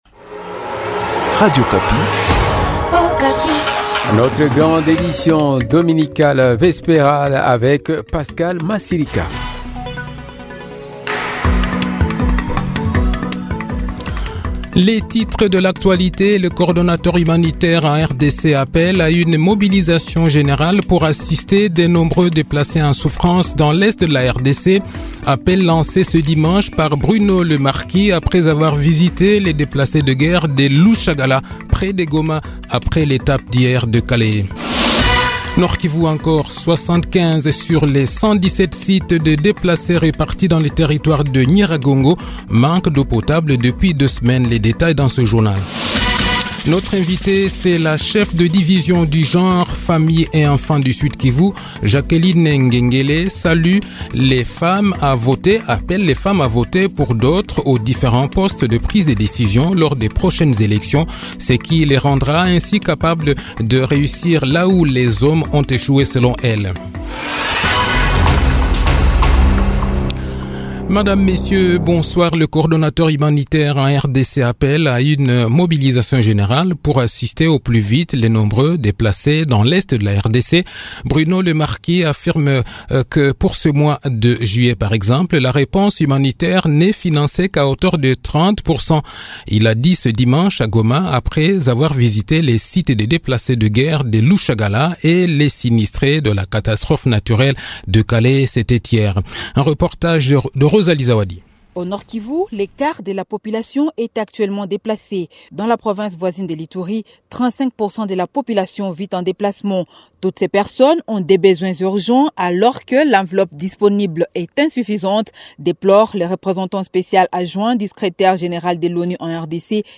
Le journal de 18 h, 9 Juillet 2023